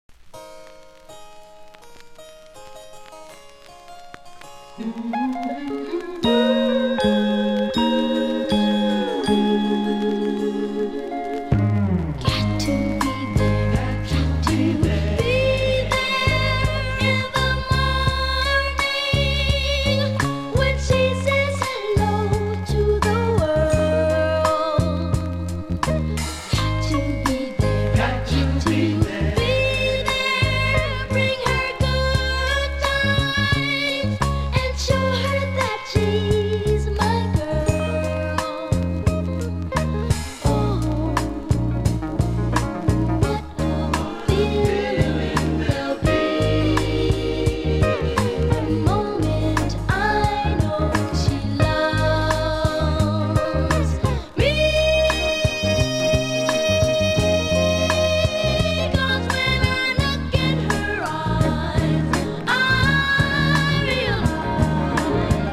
これからパフォーマーとしての未来へ羽ばたく様な歌いっぷりが気持ち良い。
(税込￥1980)   SOUL, R&B